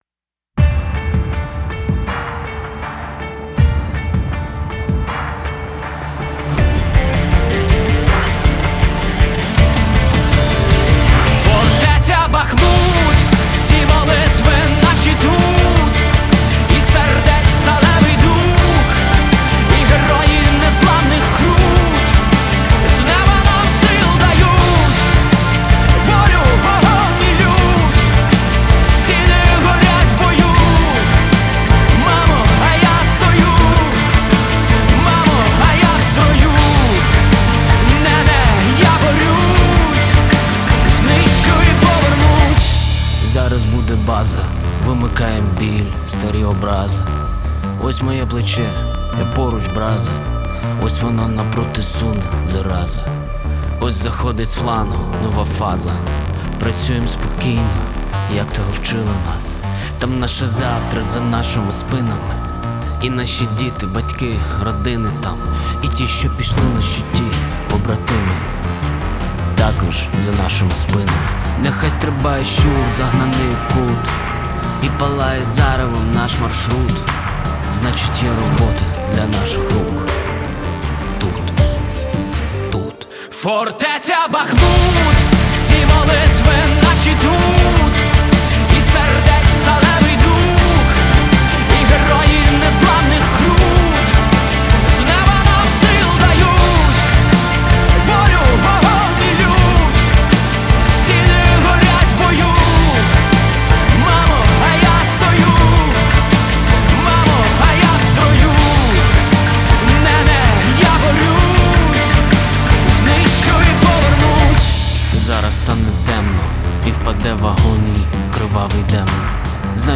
• Жанр:Рок